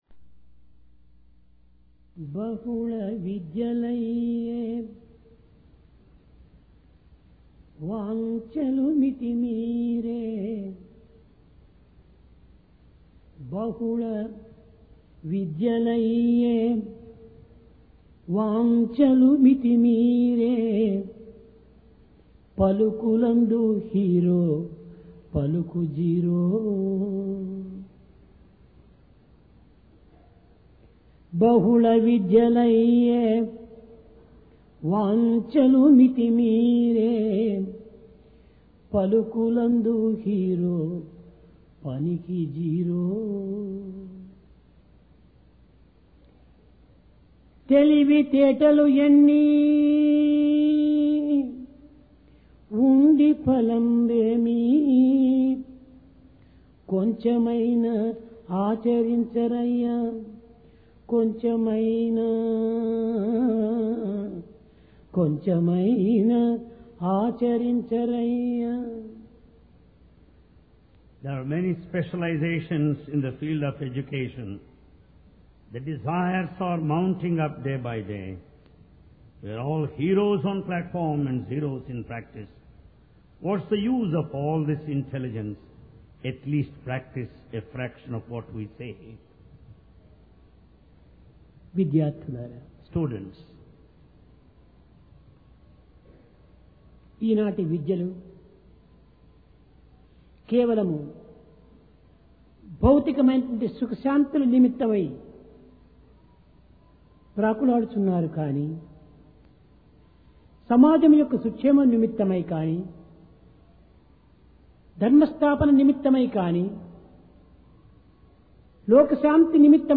Occasion: Divine Discourse Place: Prashanti Nilayam Paying Tax Of Gratitude To God